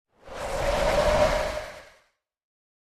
minecraft / sounds / mob / breeze / idle4.ogg